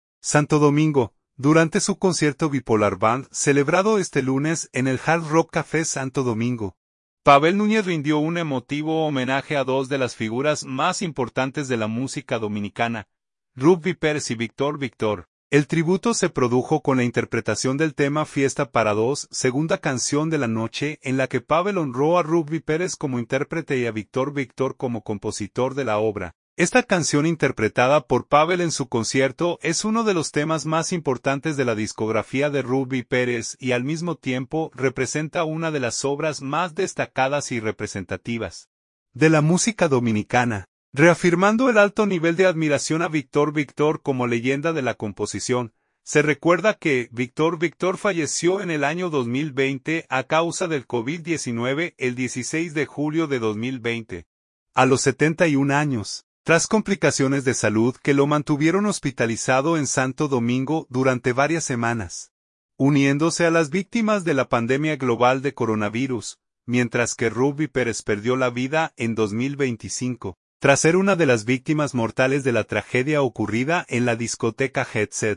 celebrado este lunes en el Hard Rock Café Santo Domingo
segunda canción de la noche